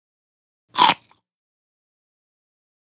SnglShrtBiteCelery PE677902
DINING - KITCHENS & EATING CELERY: INT: Single short bite into celery.